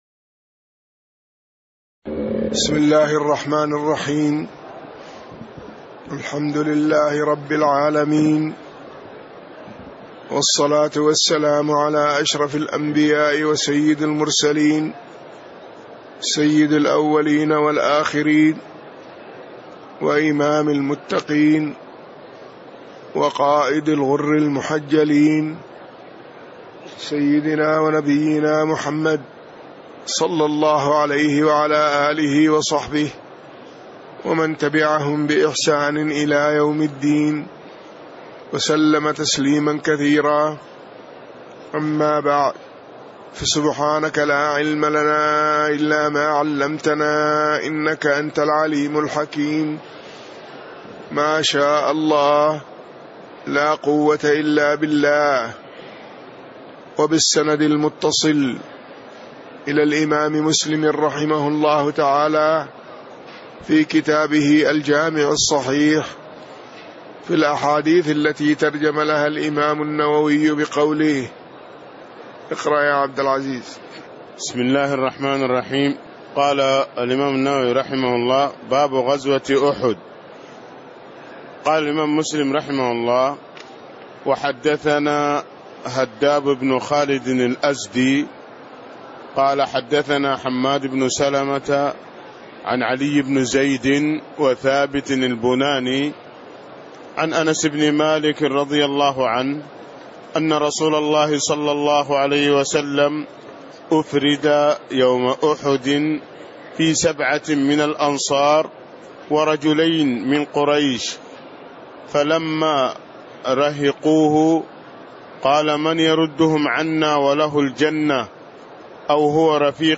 تاريخ النشر ٢ محرم ١٤٣٦ هـ المكان: المسجد النبوي الشيخ